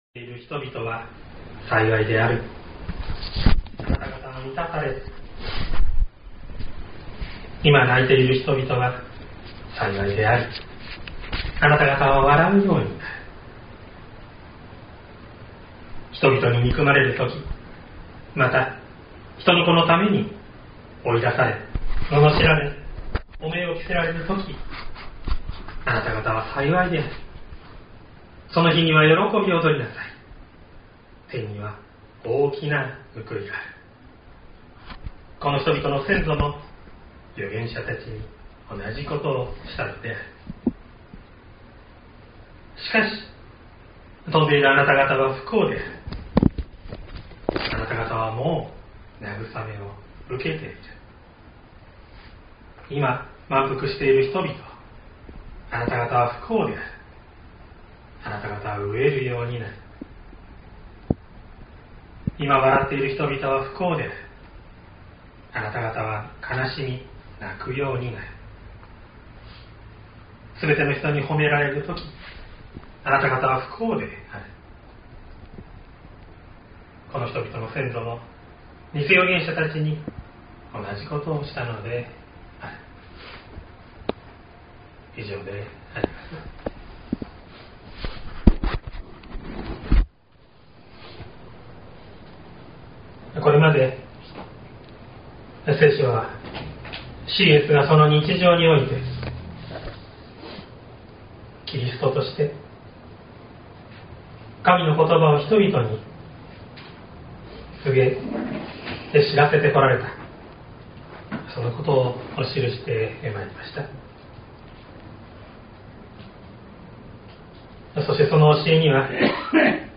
2023年07月23日朝の礼拝「神の子として生きると言うこと」西谷教会
説教アーカイブ。
音声ファイル 礼拝説教を録音した音声ファイルを公開しています。